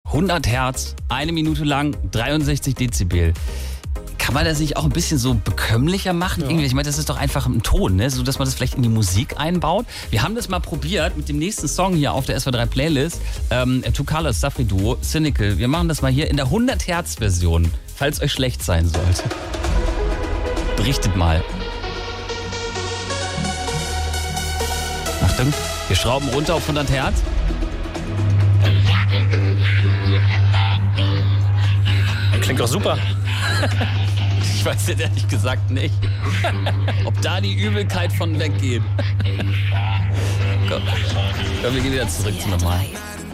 hat ihn ganz bekömmlich in einen Song eingebaut